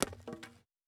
Foley Sports / Baseball - Cricket / Bat-On-Shoe.wav
Bat-On-Shoe.wav